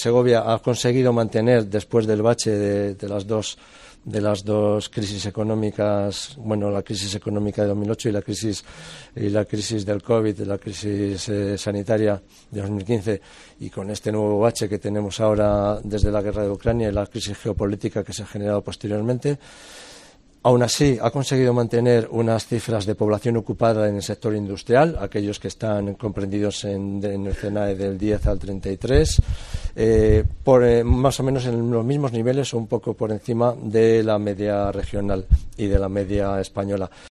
Mariano Veganzones, consejero de Industria, Comercio y Empleo